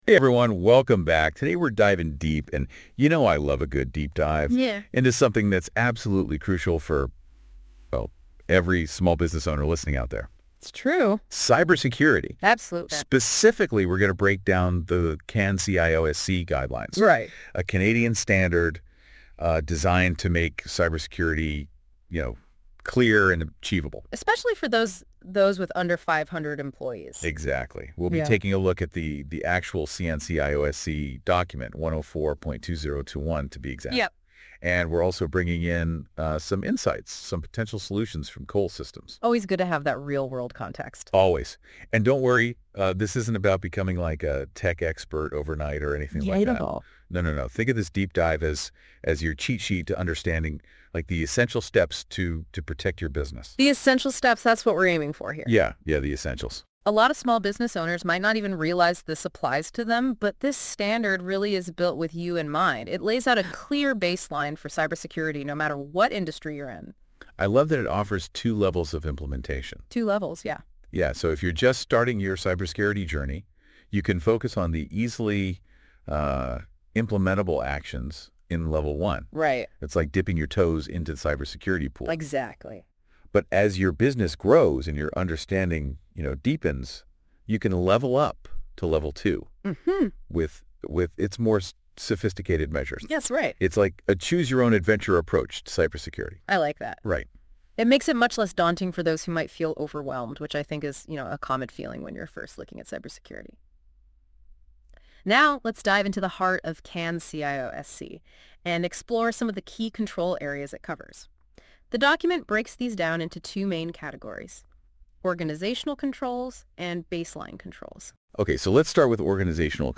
This is an AI analysis of the security controls, why they are important, and how they would apply to a typical small business owner.